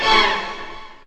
strTTE65025string-A.wav